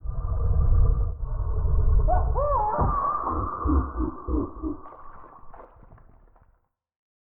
Commotion25.ogg